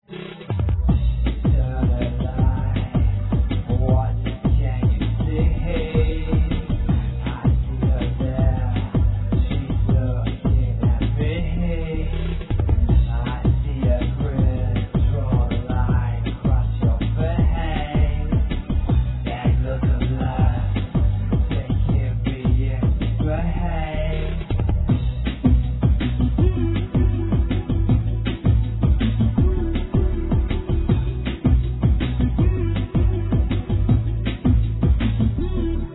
Unreleased Drum & Bass Remix